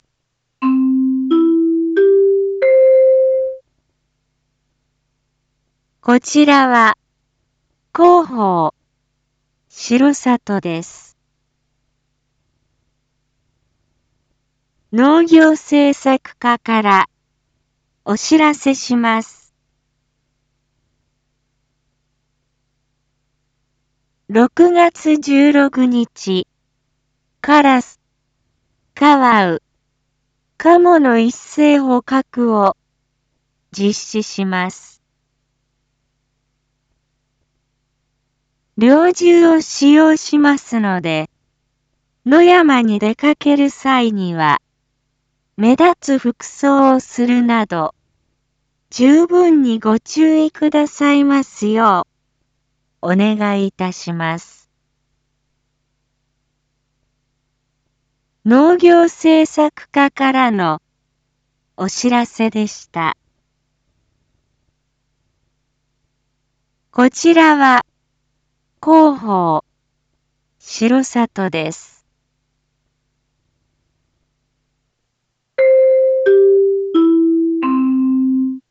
Back Home 一般放送情報 音声放送 再生 一般放送情報 登録日時：2024-06-15 19:01:20 タイトル：有害鳥獣捕獲について（カラス・カワウ・カモ） インフォメーション：こちらは、広報しろさとです。